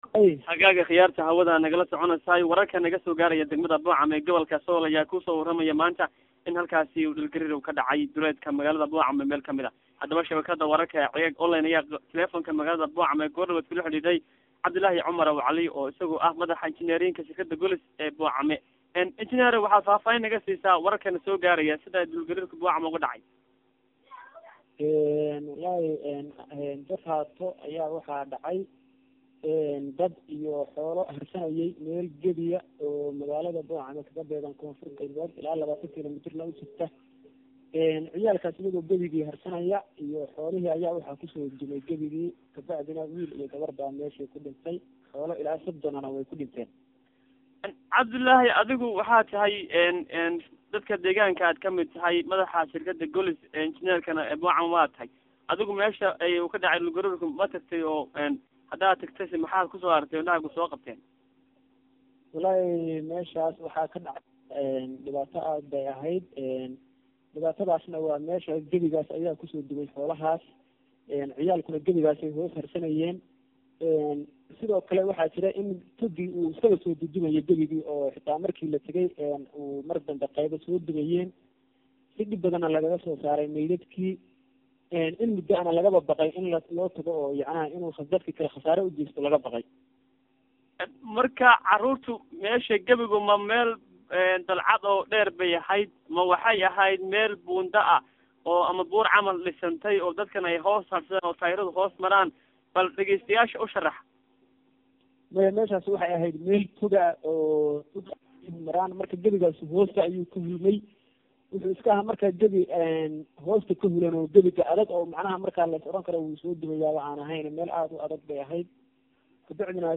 Hadaba Ceegaag online ayaa taleefoonka magaalada Boocame kula Xidhiidhay